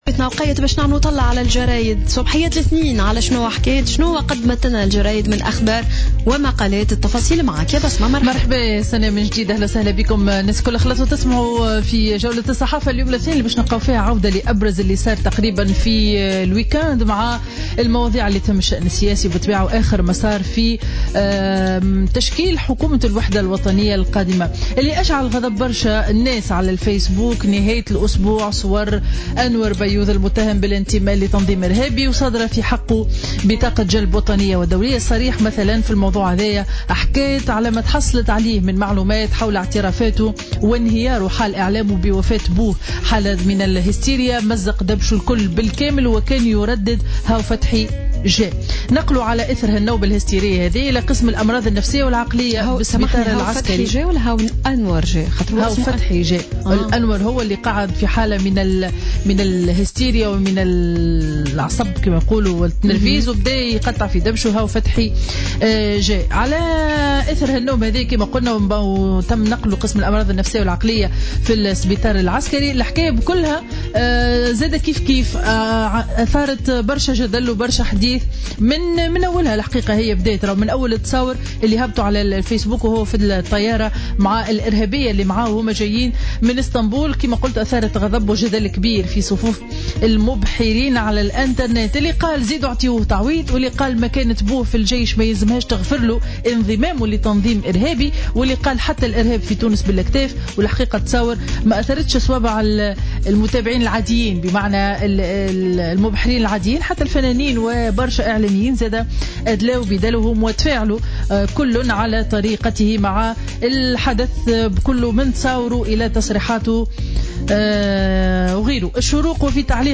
Revue de presse du lundi 4 Juillet 2016